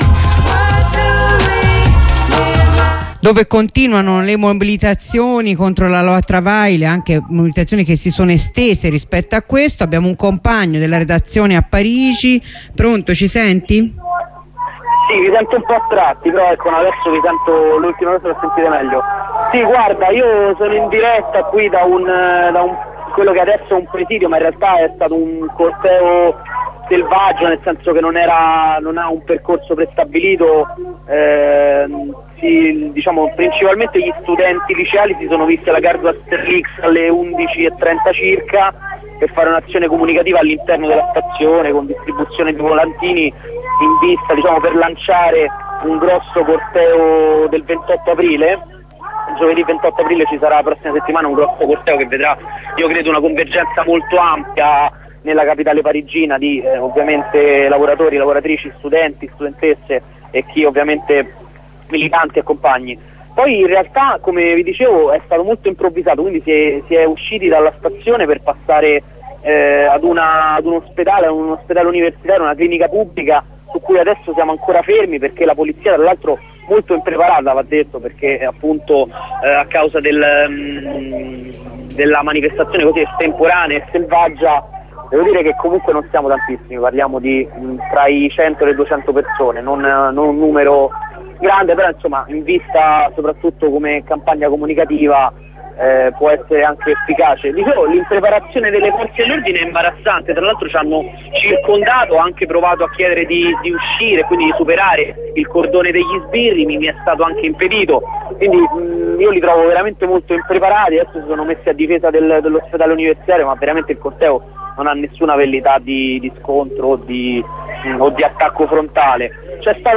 La corrispondenza con un compagno della redazione di Radio Onda Rossa.